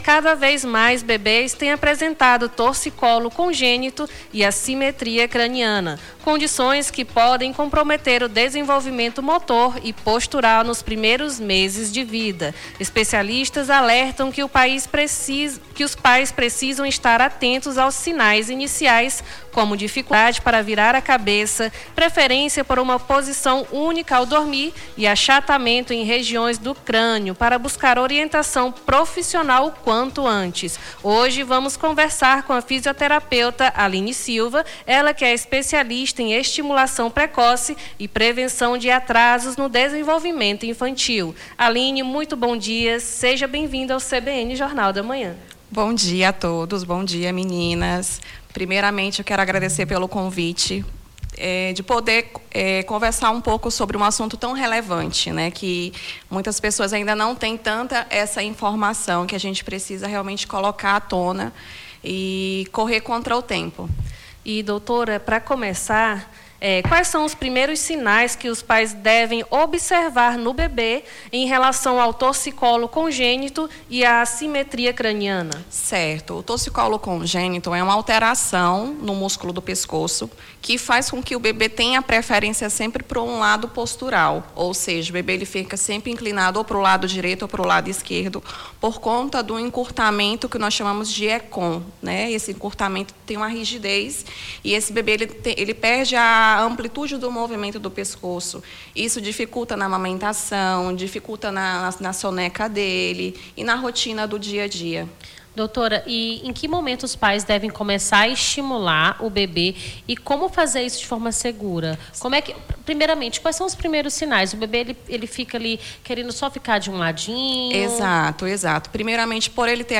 Baixar Esta Trilha Nome do Artista - CENSURA - ENTREVISTA TORCICOLO CONGÊNITO E ASSIMETRIA EM BEBÊS (20-03-26).mp3 Digite seu texto aqui...